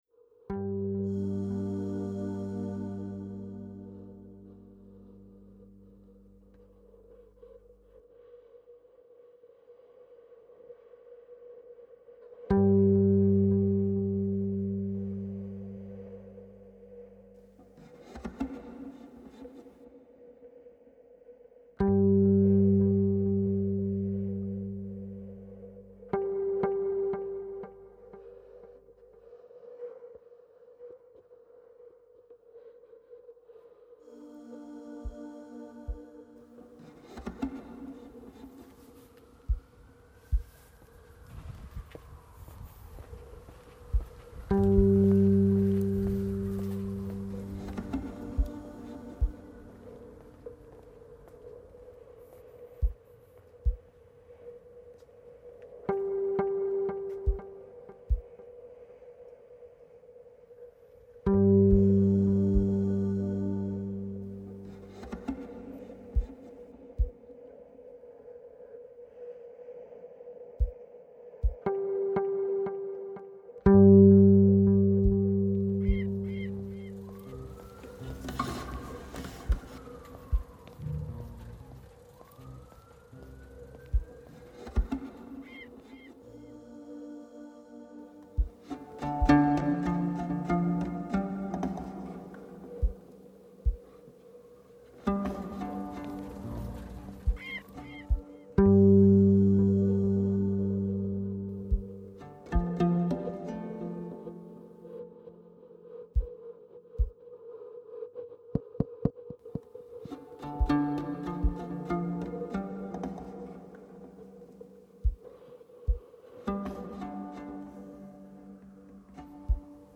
soundscapes og meditationspoesi
Hør en instrumental fordybelse